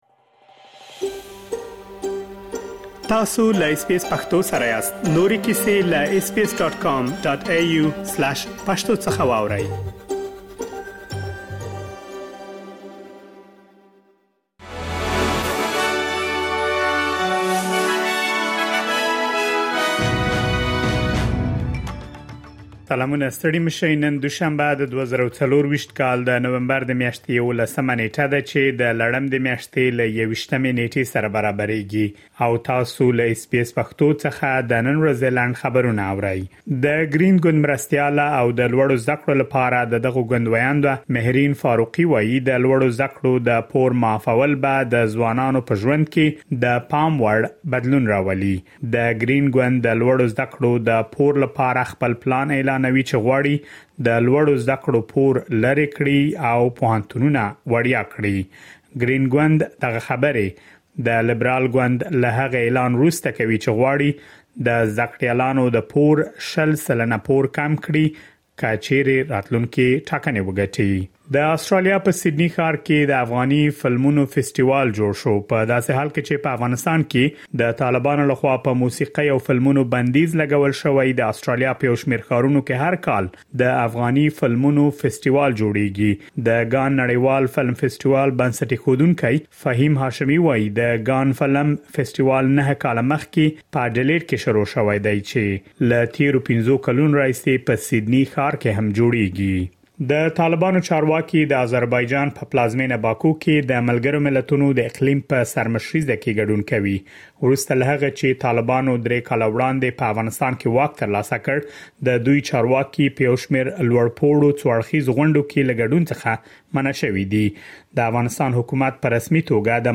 د اس بي اس پښتو د نن ورځې لنډ خبرونه |۱۱ نومبر ۲۰۲۴